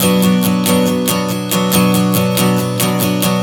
Strum 140 Bm 04.wav